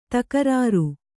♪ takarāru